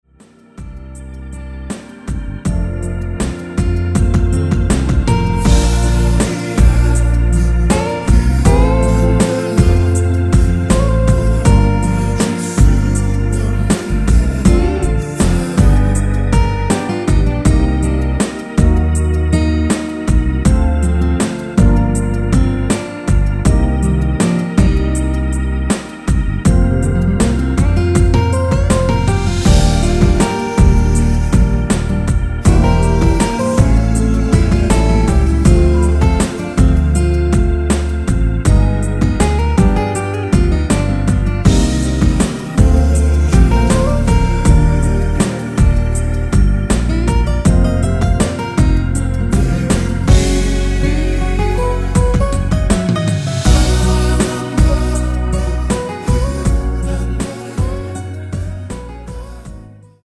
(-2) 내린코러스 포함된 MR 입니다.(미리듣기 참조)
Bb
앞부분30초, 뒷부분30초씩 편집해서 올려 드리고 있습니다.
중간에 음이 끈어지고 다시 나오는 이유는